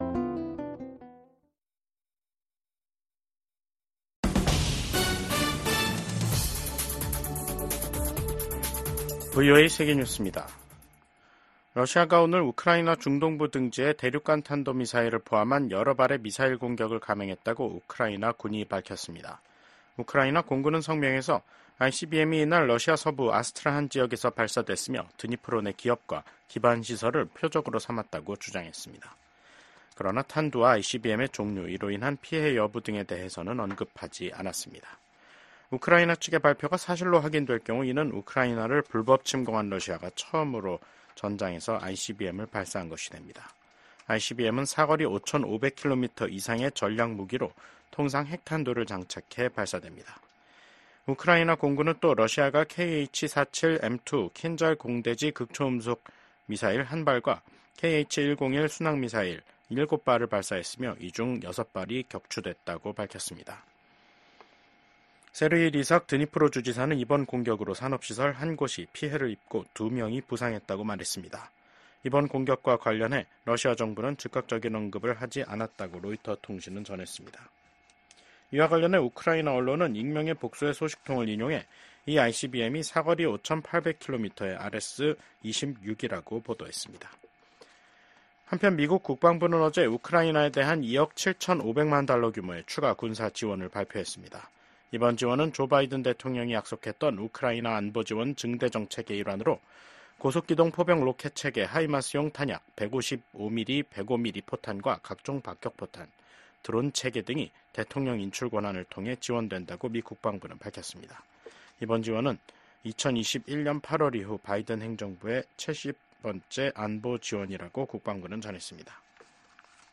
VOA 한국어 간판 뉴스 프로그램 '뉴스 투데이', 2024년 11월 21일 2부 방송입니다. 미국 국무부는 우크라이나 전선에 배치된 북한군이 합법적인 군사 표적임을 재확인했습니다. 약 2년 전 만료된 북한인권법 연장을 승인하는 법안이 미국 하원 본회의를 통과했습니다. 유엔총회 제3위원회가 20년 연속 북한의 심각한 인권 상황을 규탄하는 결의안을 채택했습니다.